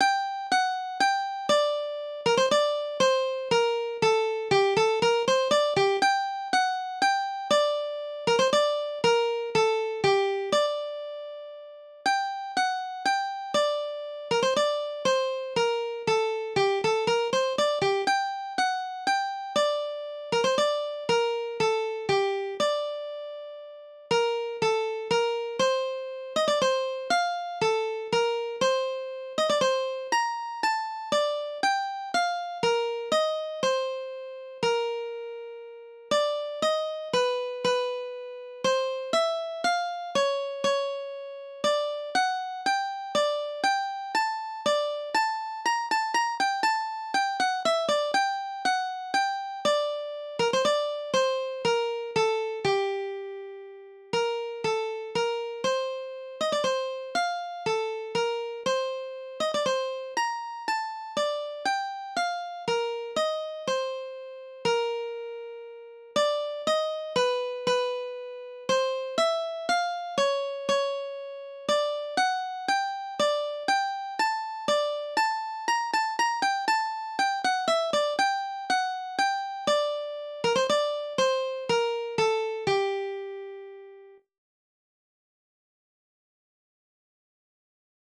DIGITAL SHEET MUSIC - MANDOLIN SOLO